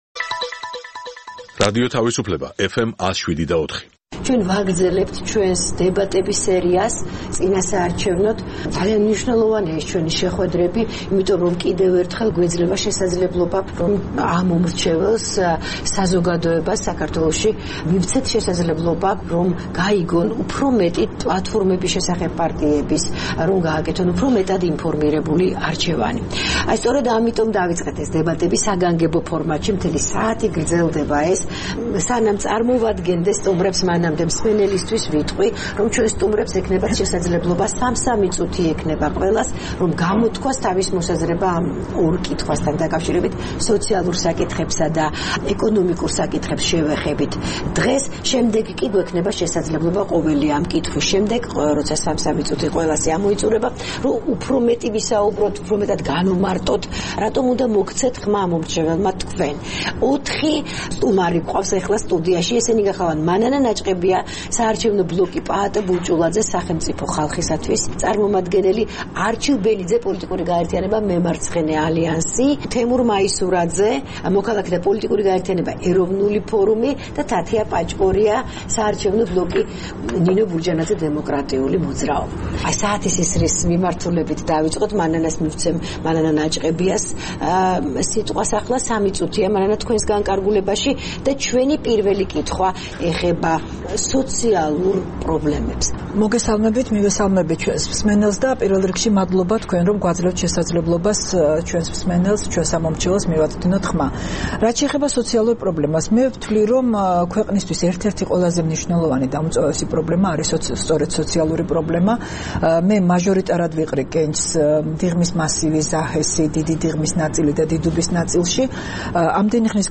დებატები „თავისუფლებაზე“ (2)
წინასაარჩევნოდ, რადიო თავისუფლების მსმენელებს შესაძლებლობა აქვთ კიდევ ერთხელ შეხვდნენ 2016 წლის საპარლამენტო არჩევნებში მონაწილე პარტიებისა და ბლოკების წარმომადგენლებს. საგანგებო, ერთსაათიან გადაცემაში, სტუმრები ორ თემაზე საუბრობენ - სოციალურ საკითხებსა და ეკონომიკურ პრობლემებზე.